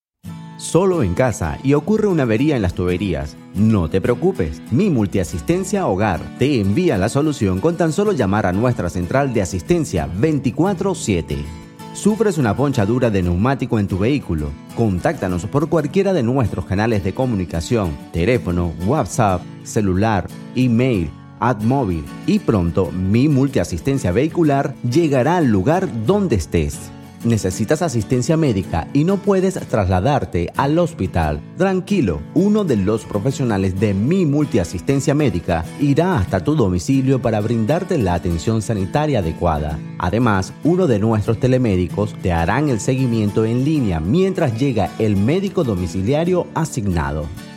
Voz para video corporativo.